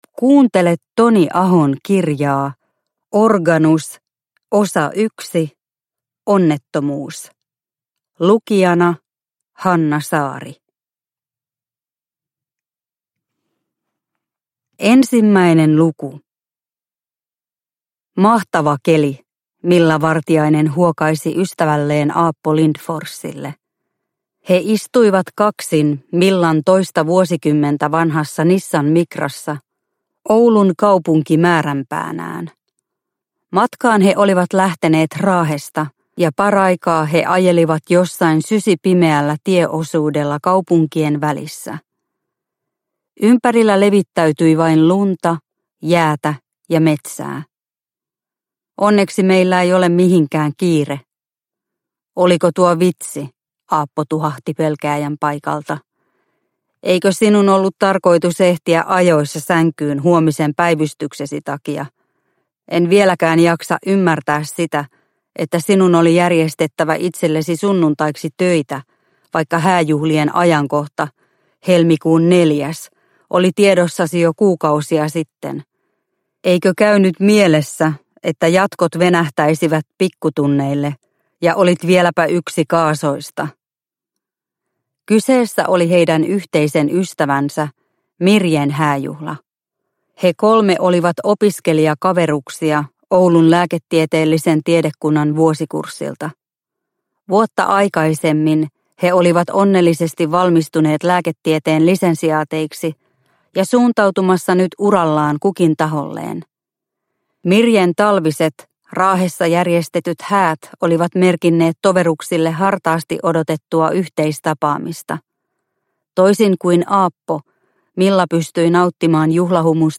Organus – Ljudbok – Laddas ner